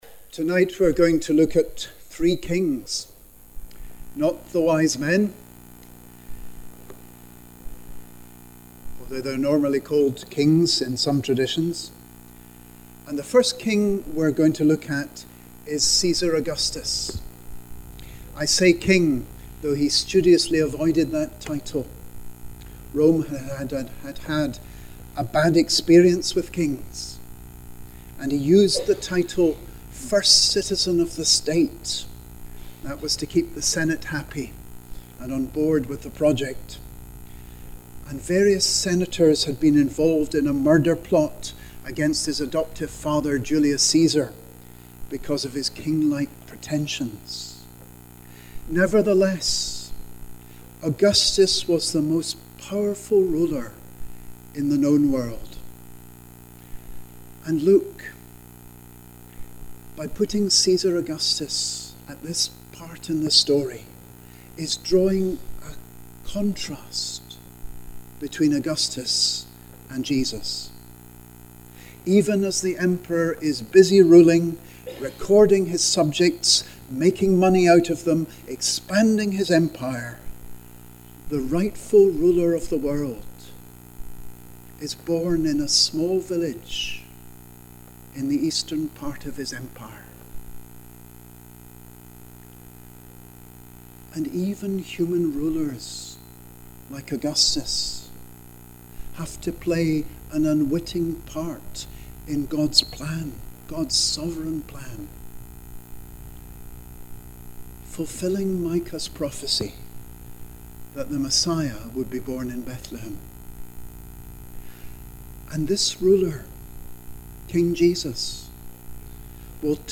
Christmas Eve at St. Mungo's - 24 December 2018
Watchnight Service and the celebration of Jesus birth